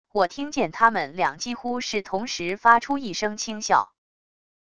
我听见他们两几乎是同时发出一声轻笑wav音频生成系统WAV Audio Player